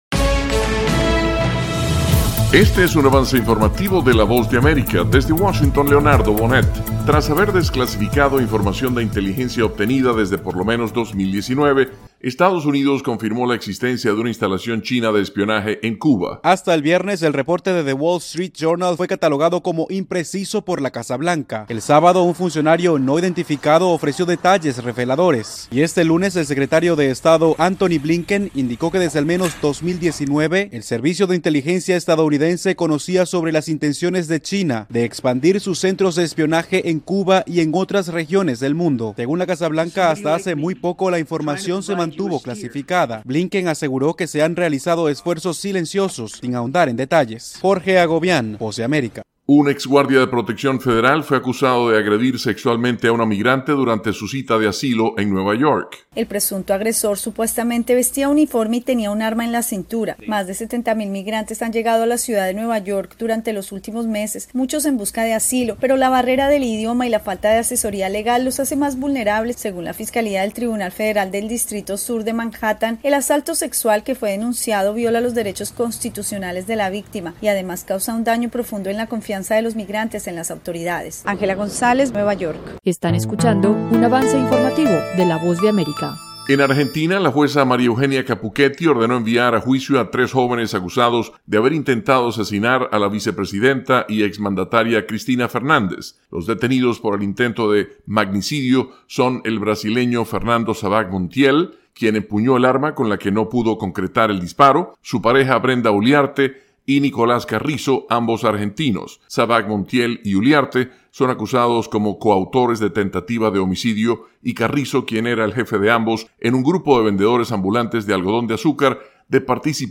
Avance Informativo 7:00 PM